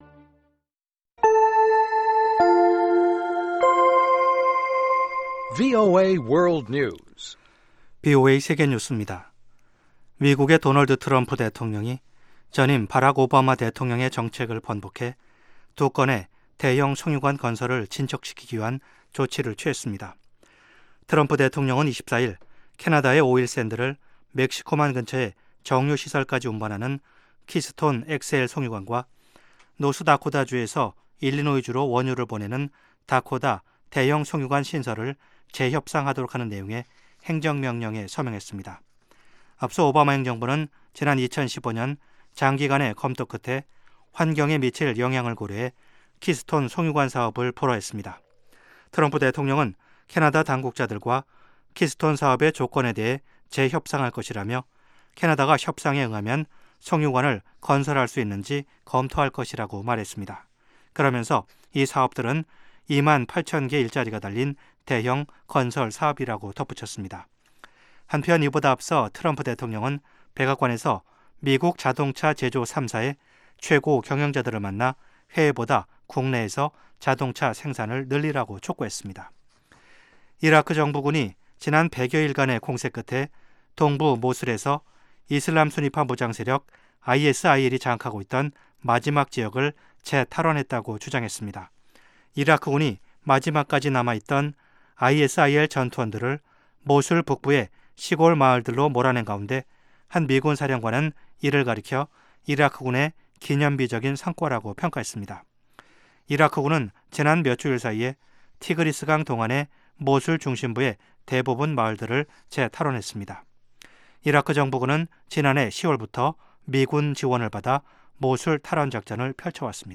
VOA 한국어 방송의 아침 뉴스 프로그램 입니다.